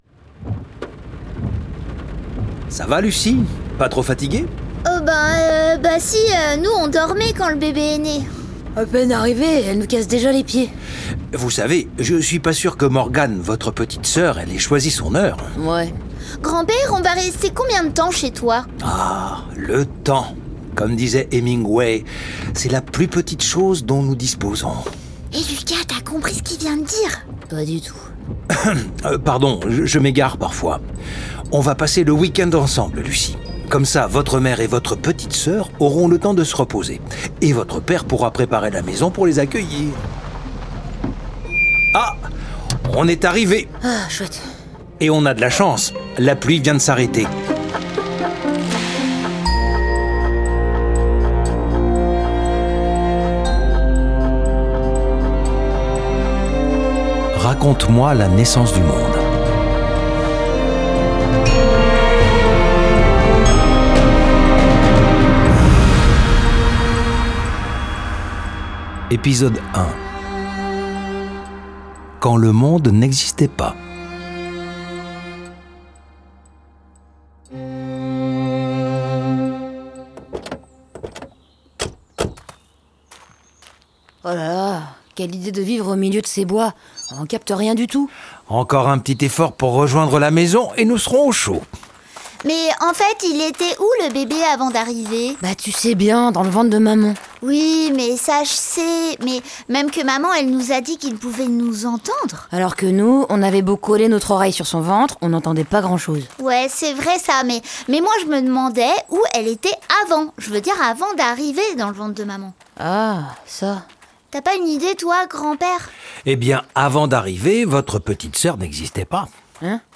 Sous la forme d’un récit dialogué entre un grand-père passionné de sciences et ses petits-enfants, ce livre audio invite les enfants, dès 8 ans, à découvrir les origines de notre planète comme une épopée pleine de rebondissements.